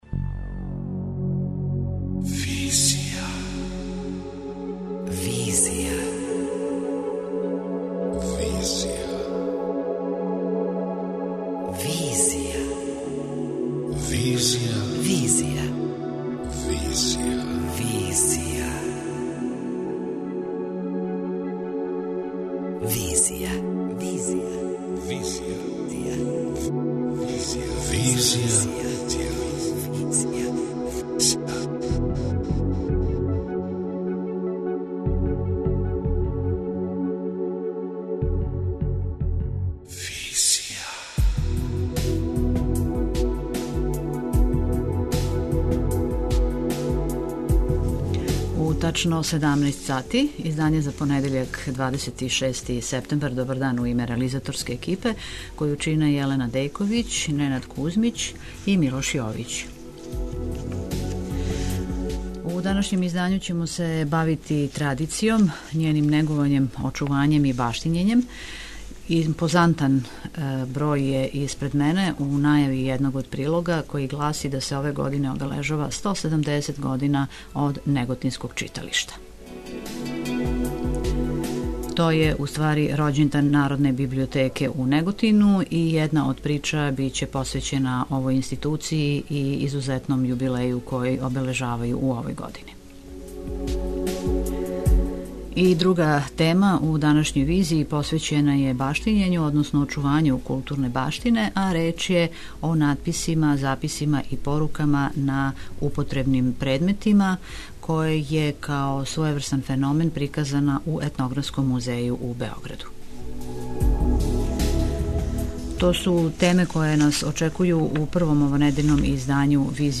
Бројне активности ове библиотеке, као једног од првих читалишта у Србији, биле су поводи да разговарамо са управом ове институције о учешћу у култури Неготина.